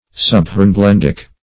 Search Result for " subhornblendic" : The Collaborative International Dictionary of English v.0.48: Subhornblendic \Sub`horn*blend"ic\, a. (Min.)